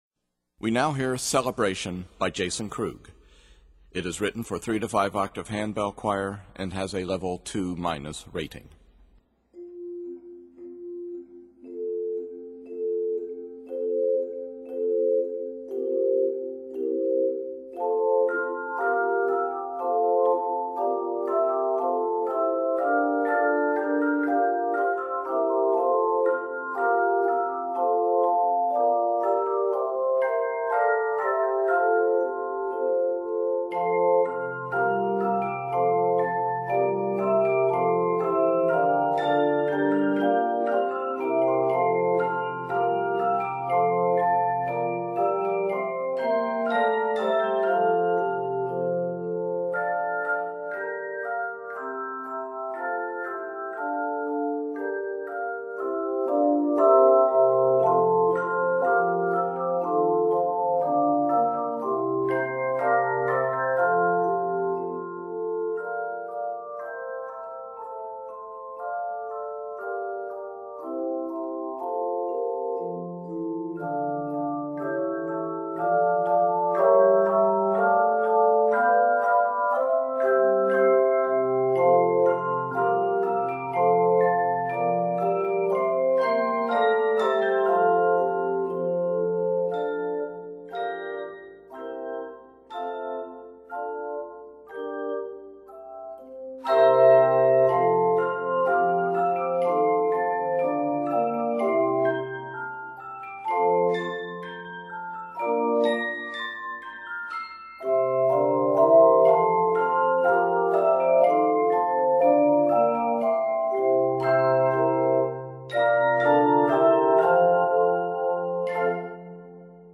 Written in F Major